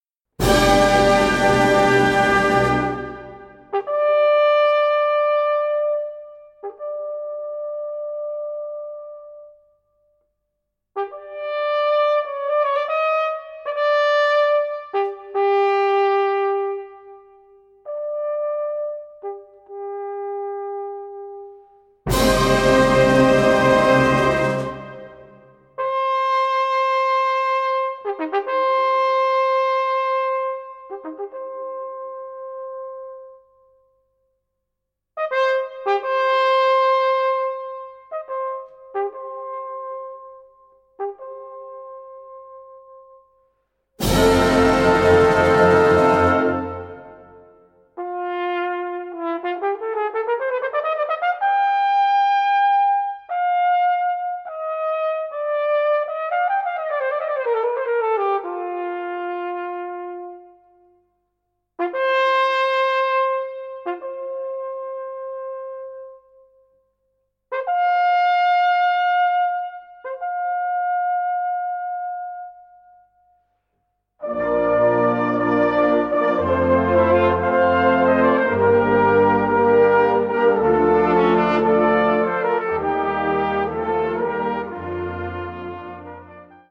Flügelhorn